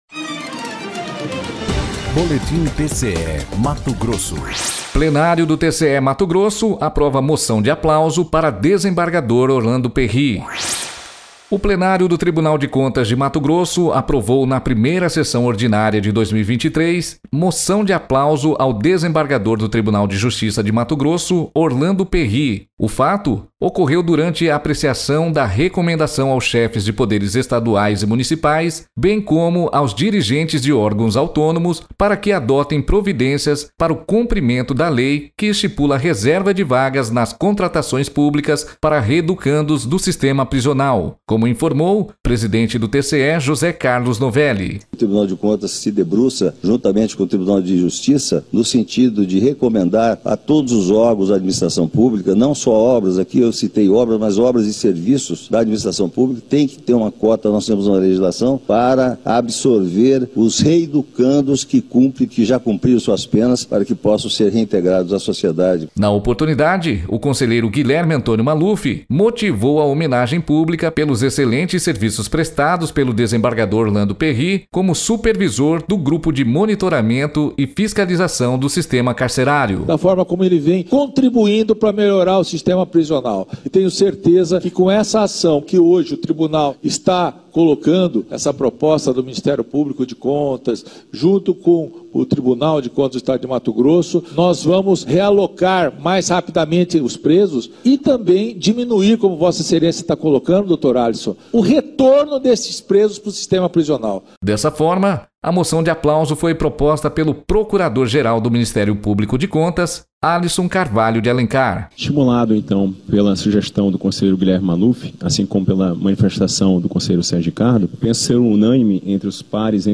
Sonora: José Carlos Novelli – conselheiro presidente do TCE-MT
Sonora: Guilherme Antonio Maluf – conselheiro do TCE-MT
Sonora: Alisson Carvalho de Alencar - procurador-geral do MPC-MT
Sonora: Sérgio Ricardo – conselheiro do TCE-MT